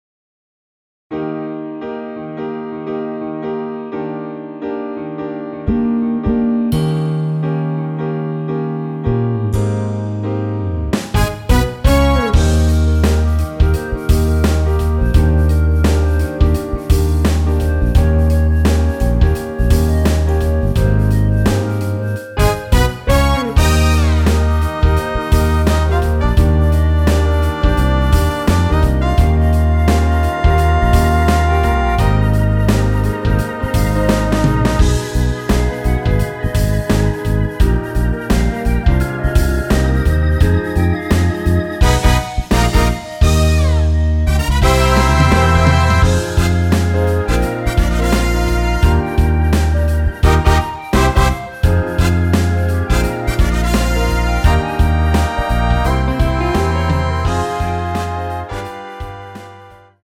원키에서(+2)올린 멜로디 포함된 MR입니다.
Eb
앞부분30초, 뒷부분30초씩 편집해서 올려 드리고 있습니다.
중간에 음이 끈어지고 다시 나오는 이유는